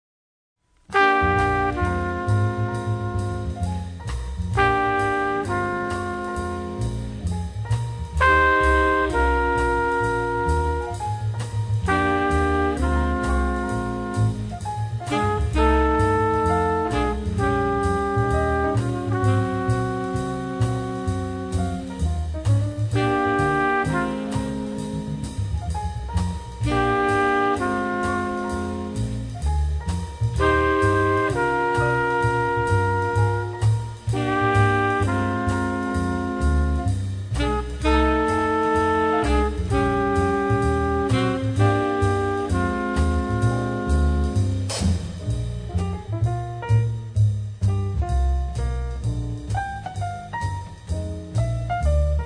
Le swing est intraitable, les harmonies magiques.